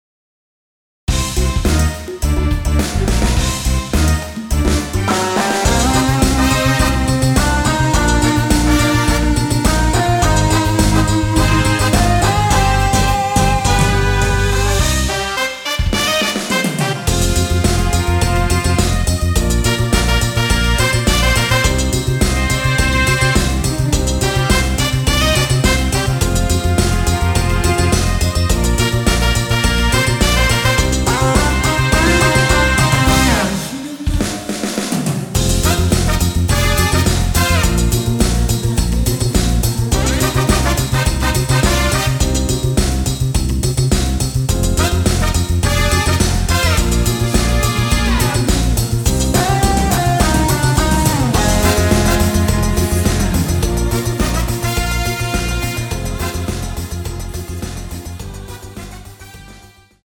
원키 코러스 포함된 MR입니다.(미리듣기 확인)
Cm
앞부분30초, 뒷부분30초씩 편집해서 올려 드리고 있습니다.
중간에 음이 끈어지고 다시 나오는 이유는